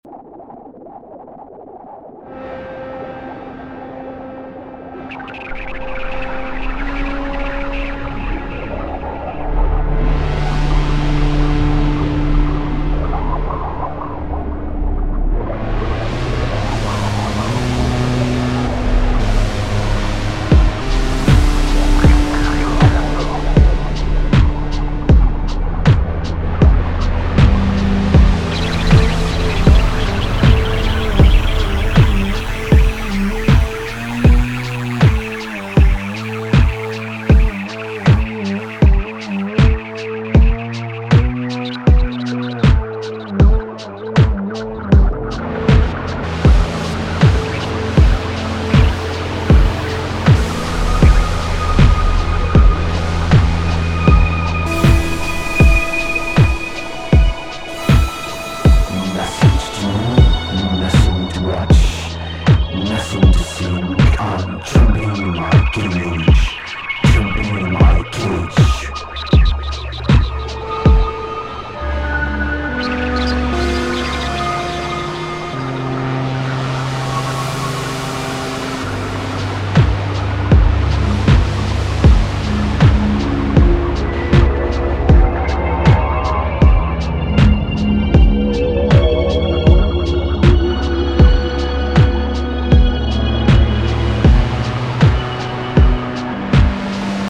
Énervé Électronique